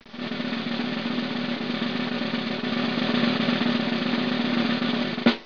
Drum
Drum.wav